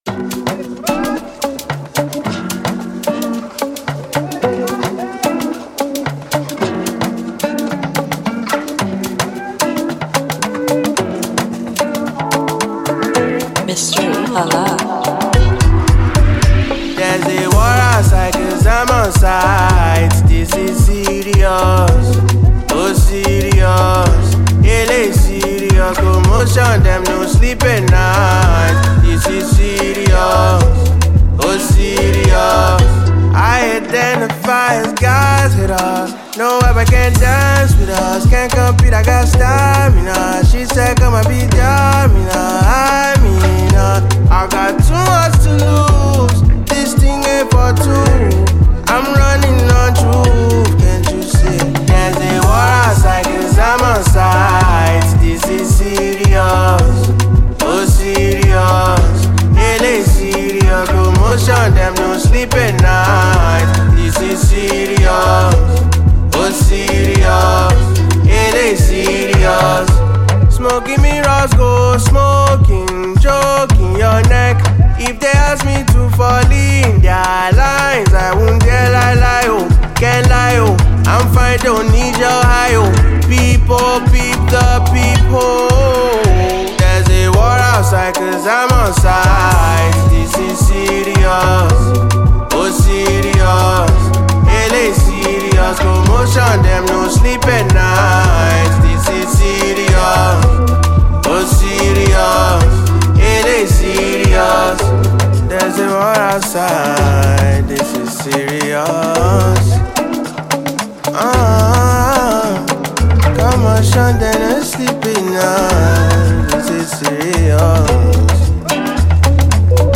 Well renowned Nigerian singer, songwriter, and producer
gbedu song